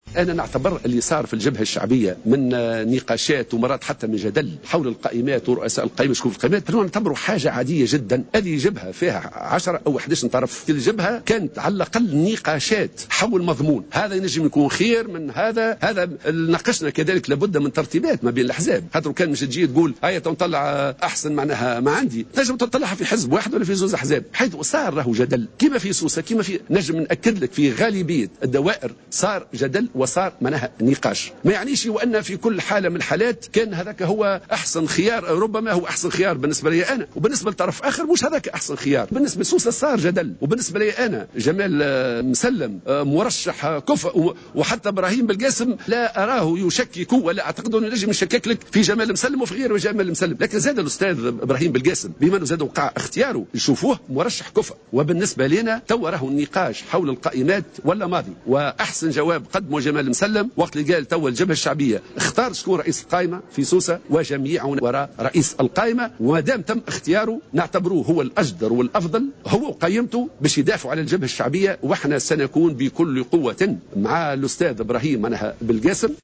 أكد الناطق الرسمي باسم الجبهة الشعبية حمة الهمامي على هامش مشاركته في لقاء شعبي في سوسة الأحد بسوسة أن الجدل القائم بخصوص تعيين رؤساء القائمات الانتخابية للتشريعية يعد أمرا عاديا خاصة مؤكدا أن النقاش والمفاوضات حول رئاسة القائمات حدث في أغلب الجهات بين الأحزاب المشاركة في الجبهة الشعبية خاصة.